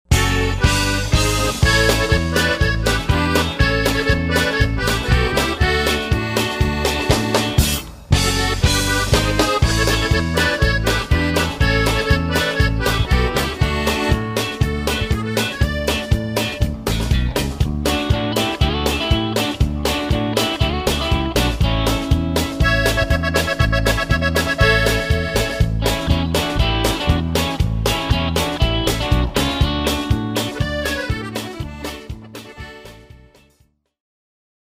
Norteña